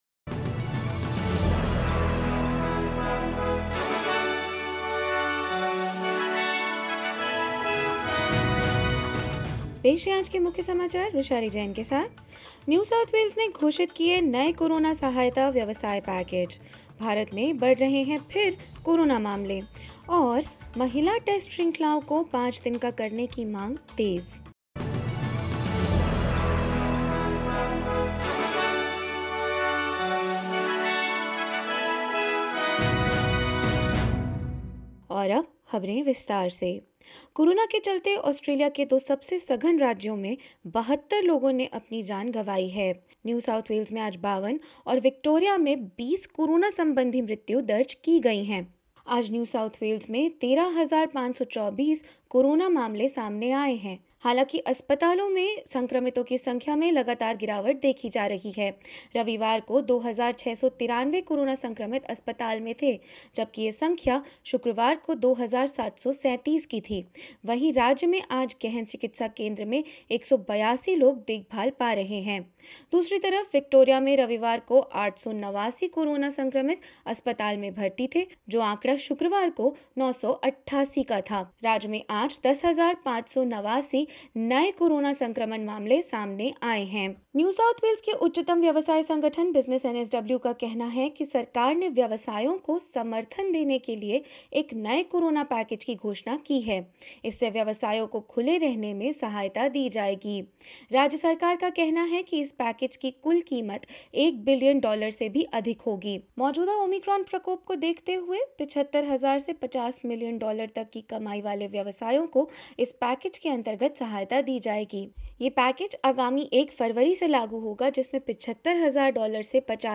SBS Hindi News 30 January 2022: NSW releases a billion dollar COVID-19 business support package